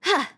Female_attack_1.wav